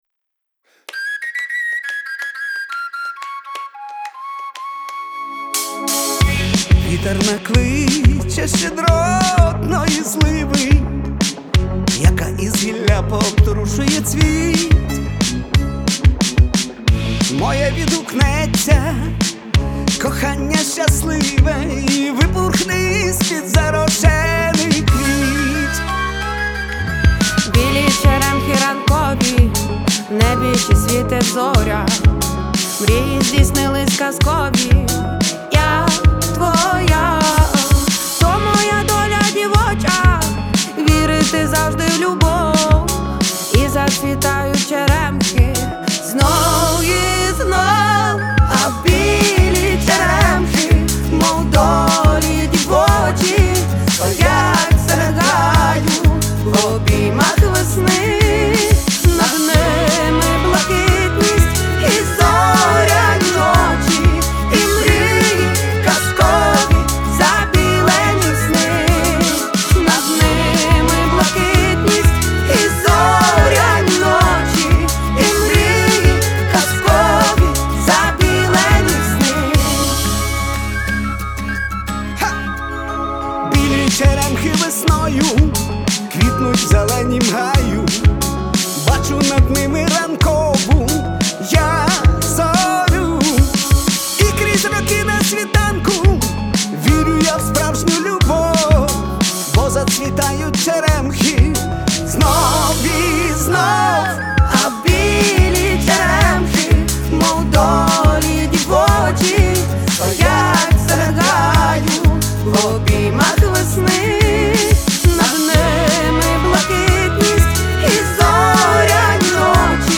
• Жанр: Pop, Folk